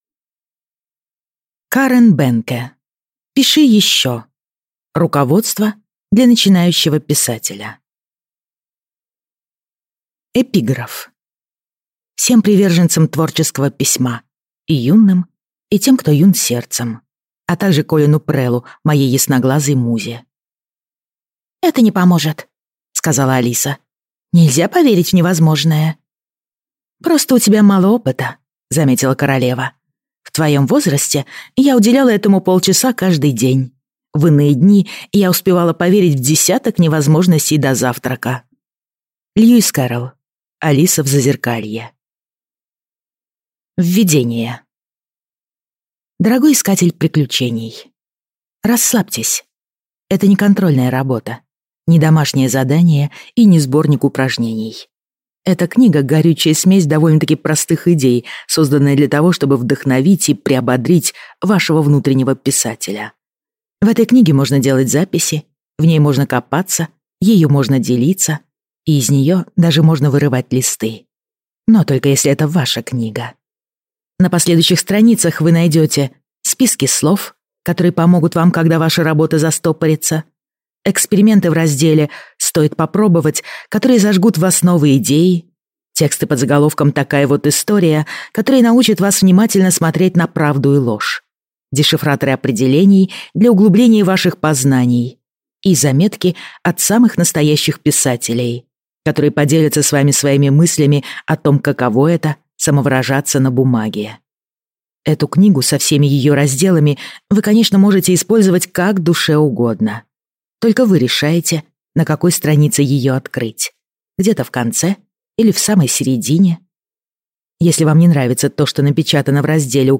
Аудиокнига Пиши ещё! Руководство для начинающего писателя | Библиотека аудиокниг